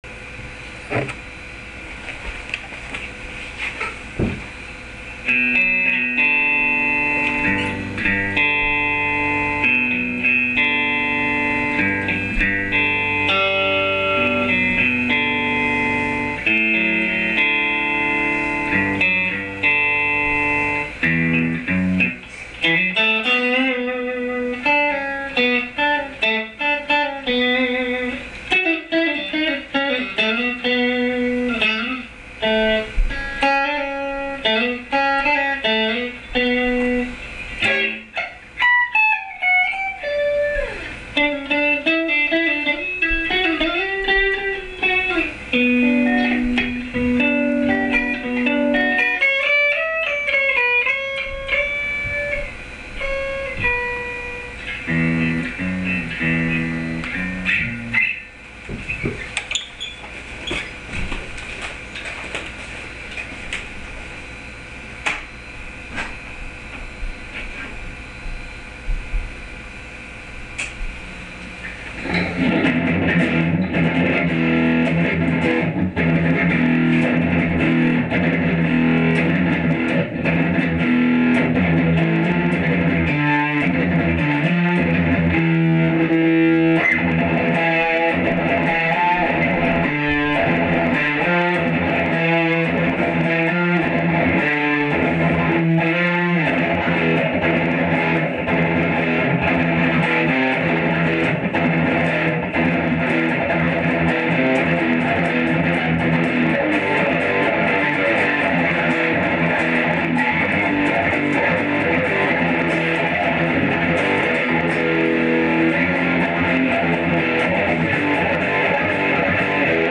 I plugged the head into a Traynor Bass cab with a single 8ohm 15 speaker
No effects used, natch!
Reminder: These were recorded after using an attenuator into a 15" speaker.
TC-15-CleanNDirtyOne.mp3